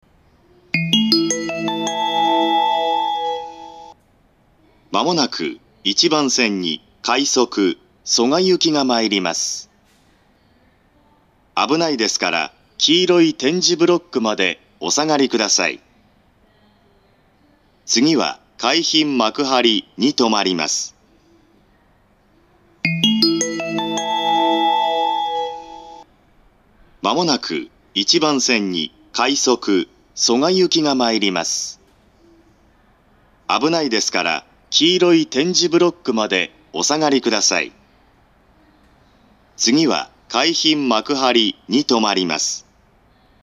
１番線接近放送A
男声の自動放送でした。
minami-funabashi1bansen-sekkin7.mp3